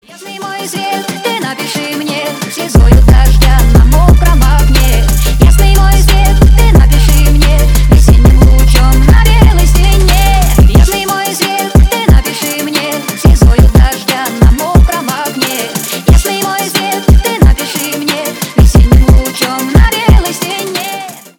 Ремикс # Поп Музыка
громкие